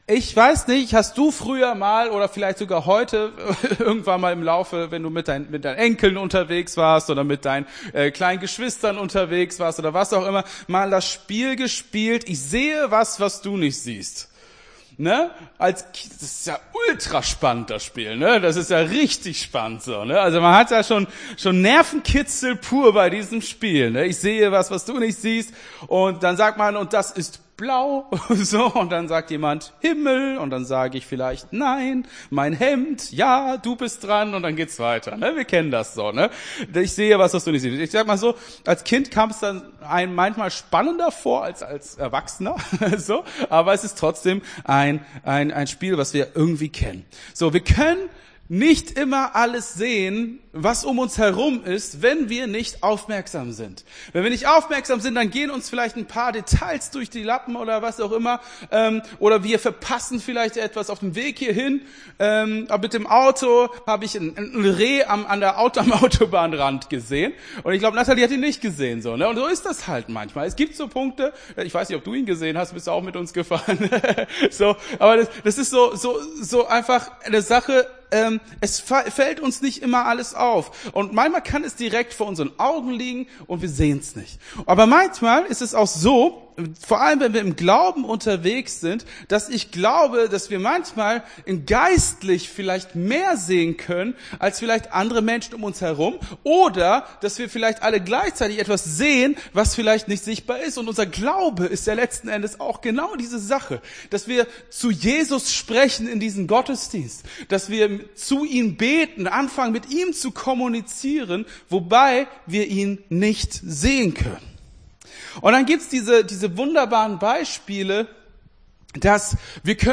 Gottesdienst 10.07.22 - FCG Hagen